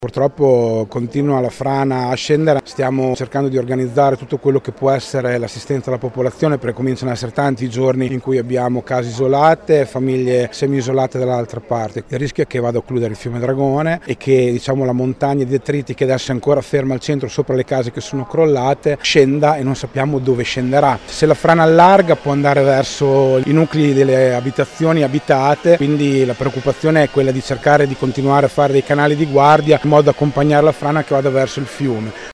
Ad aggiornarci il sindaco di Palagano Fabio Braglia: